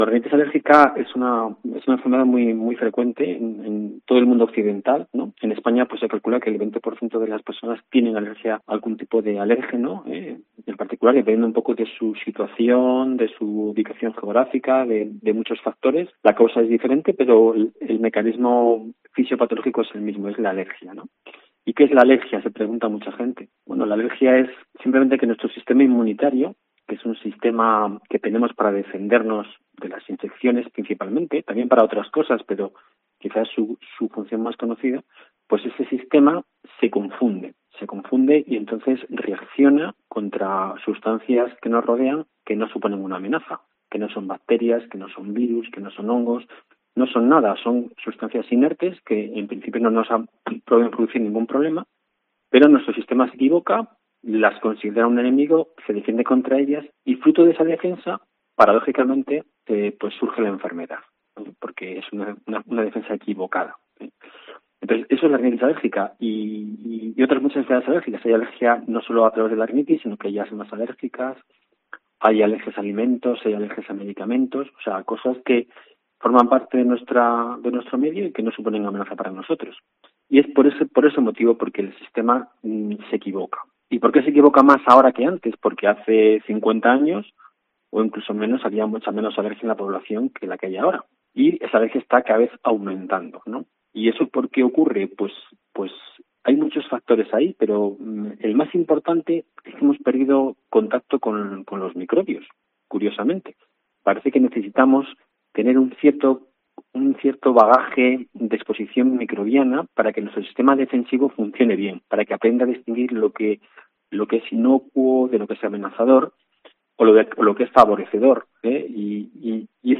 COPE Salamanca entrevista